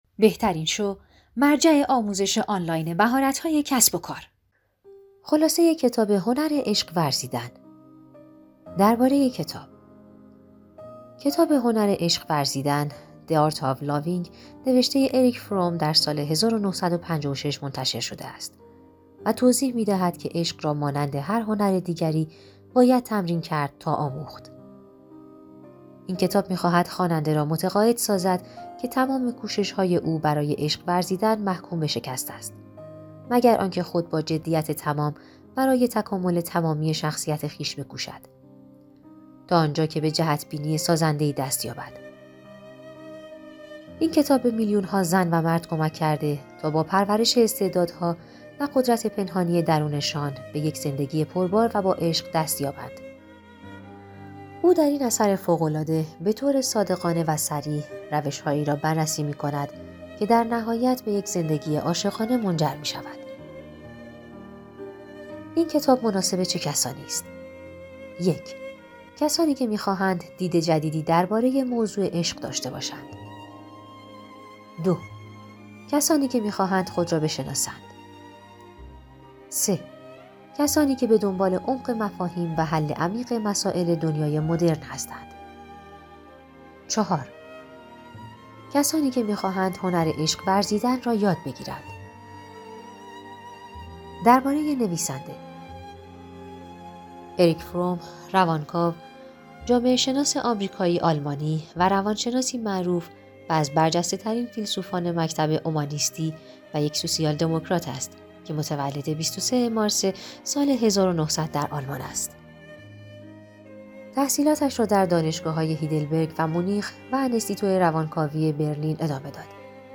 کتاب صوتی موجود است